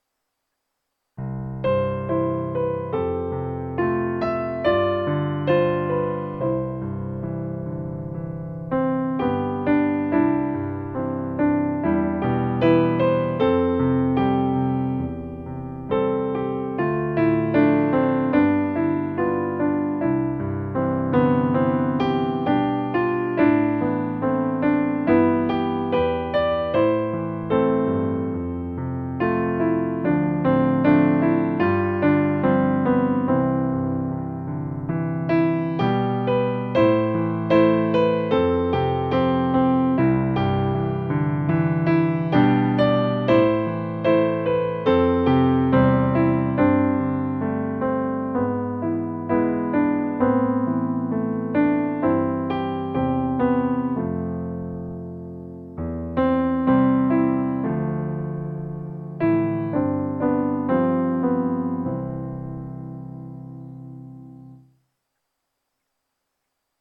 Voicing/Instrumentation: SATB , Choir Unison , Vocal Solo